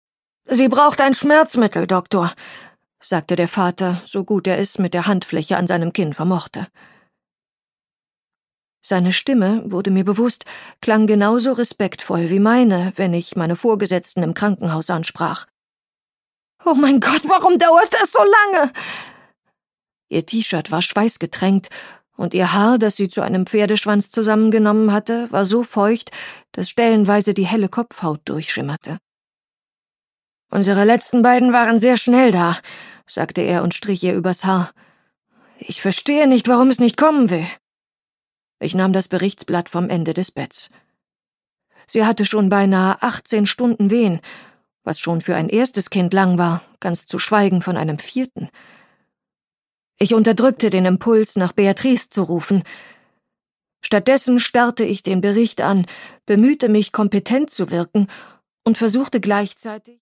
Produkttyp: Hörbuch-Download
Gelesen von: Luise Helm